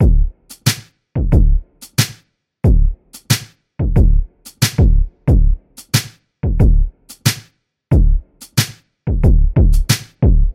嗨，划痕
描述：1990年代的嘻哈样本东海岸风格
标签： 91 bpm Hip Hop Loops Scratch Loops 1.78 MB wav Key : Unknown
声道立体声